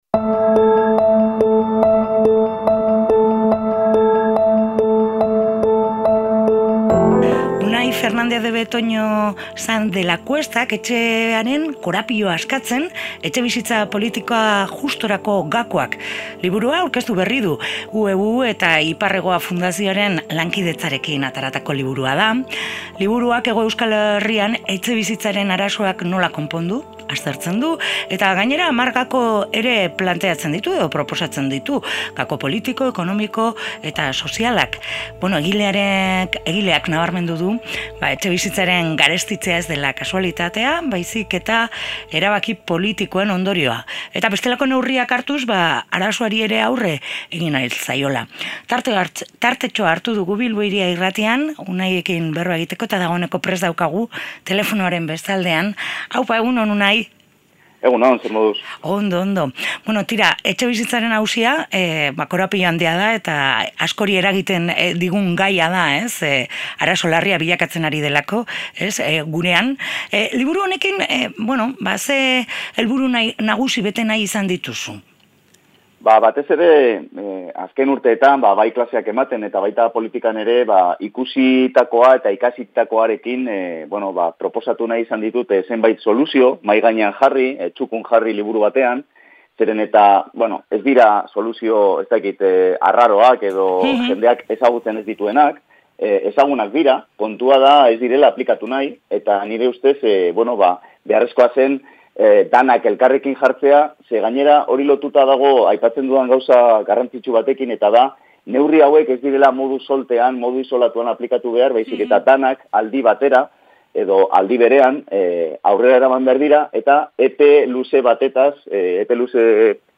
Elkarrizketa honetan